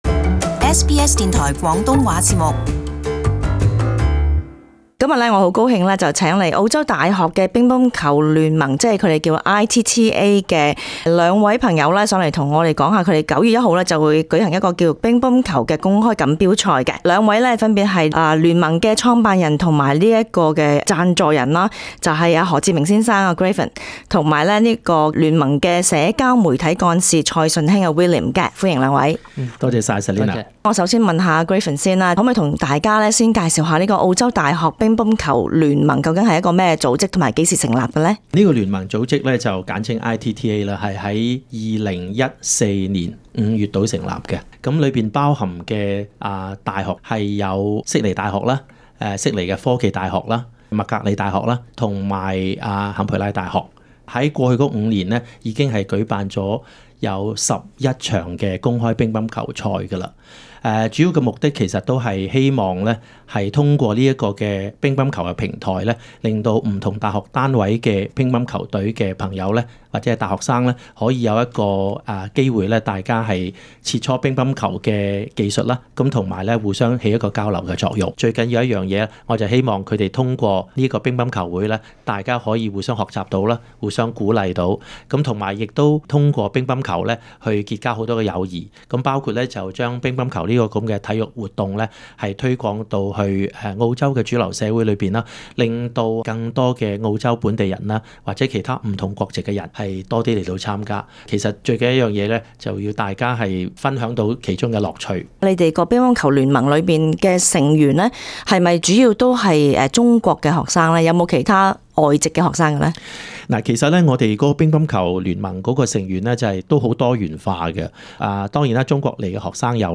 【社區專訪】雪梨乒乓球公開錦標賽